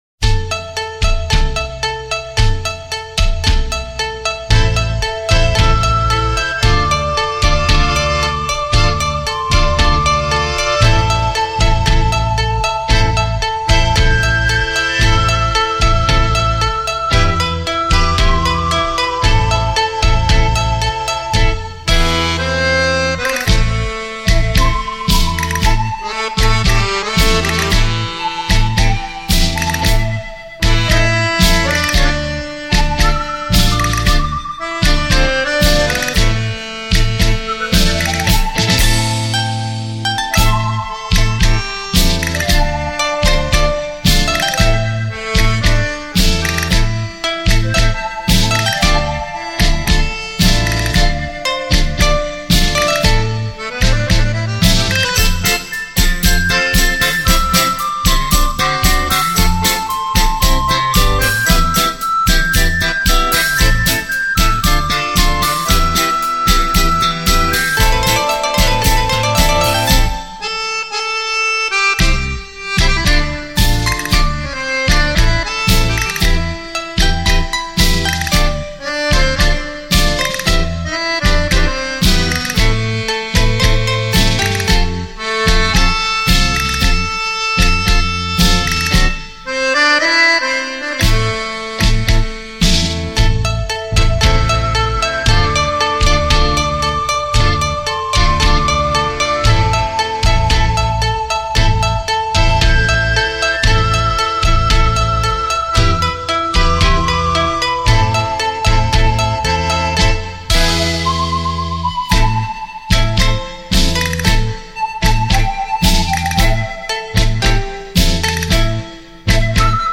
专辑格式：DTS-CD-5.1声道
繞場立體音效 發燒音樂重炫